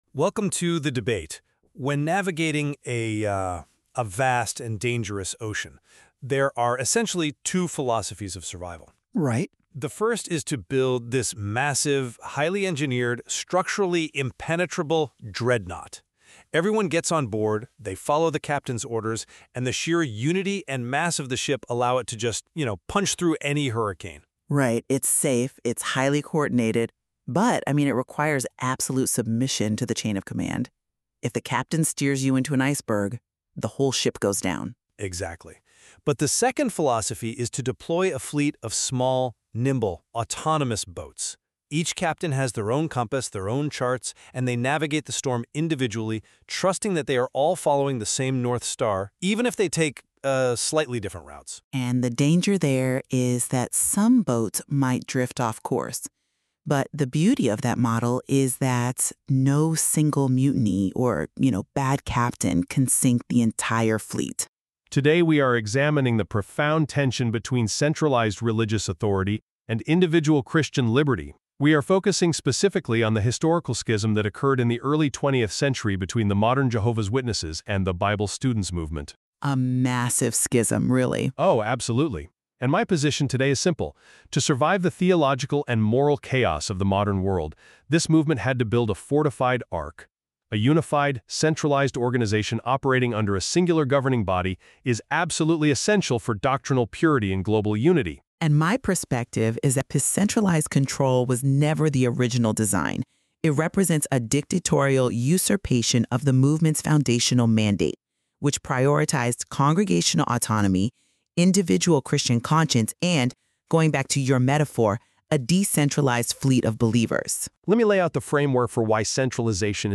A Note on Our Use of Technology In keeping with our goal to “plant seeds of hope” using innovative tools, we have utilized advanced AI technology to simulate this respectful but spirited debate. While the voices are AI-generated, the content is strictly grounded in facts.